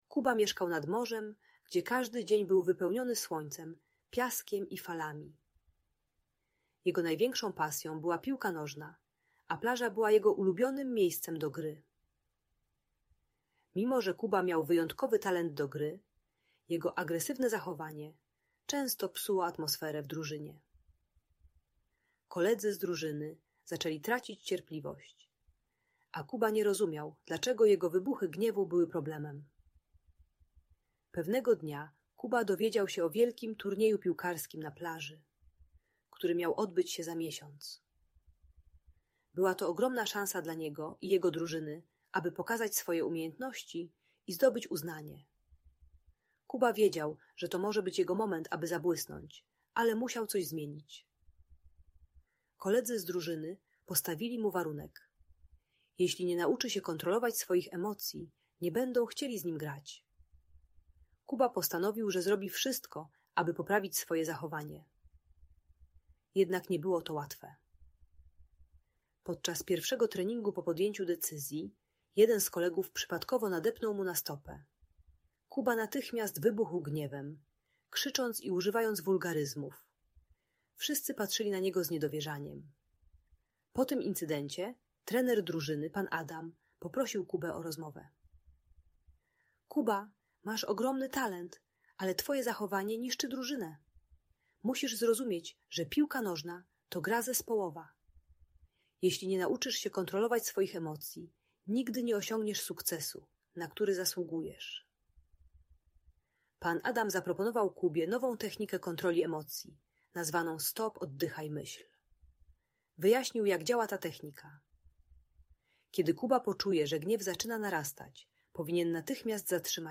Piłkarskie emocje i przemiana - Audiobajka